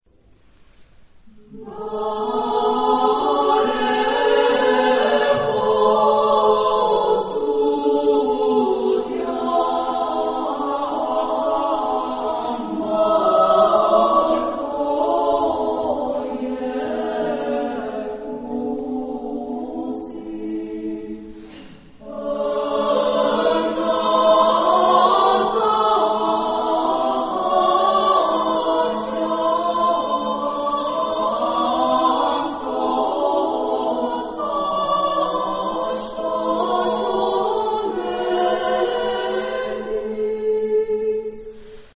Género/Estilo/Forma: Himno (sagrado) ; Sagrado
Tipo de formación coral: SSA  (3 voces Coro femenino )
Tonalidad : la menor
Ref. discográfica: Internationaler Kammerchor Wettbewerb Marktoberdorf 2007